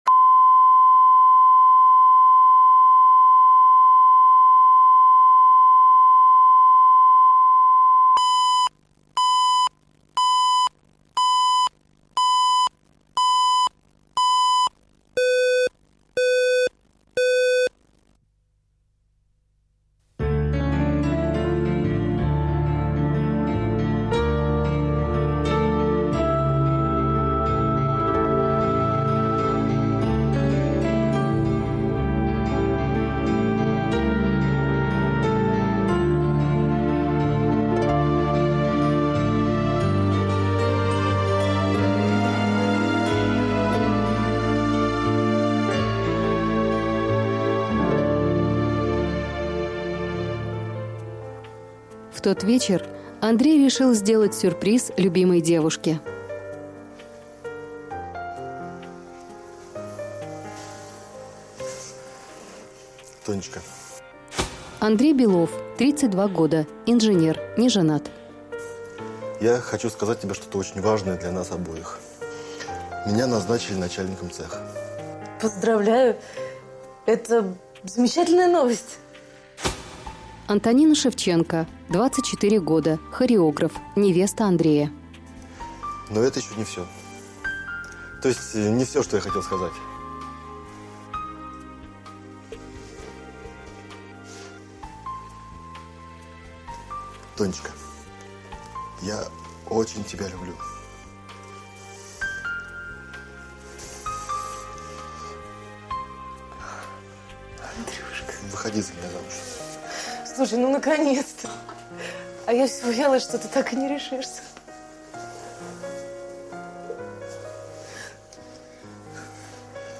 Аудиокнига Останься со мной | Библиотека аудиокниг
Прослушать и бесплатно скачать фрагмент аудиокниги